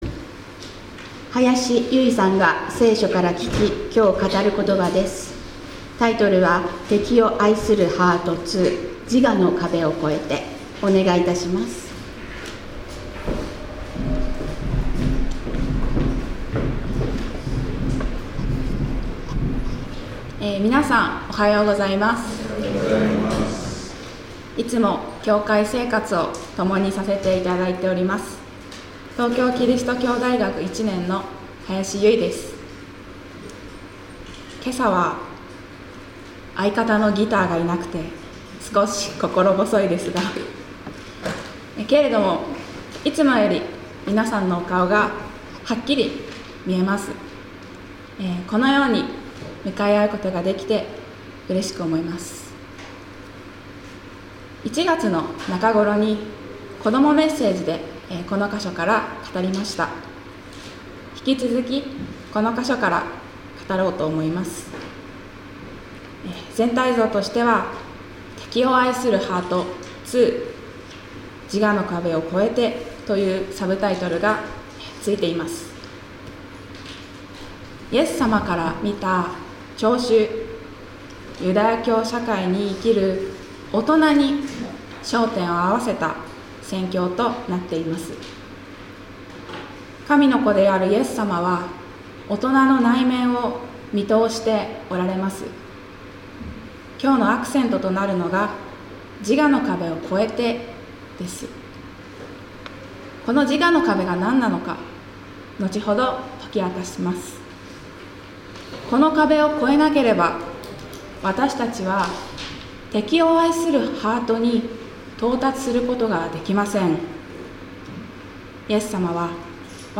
2025年3月2日礼拝「敵を愛するハート－自我の壁を越えて－」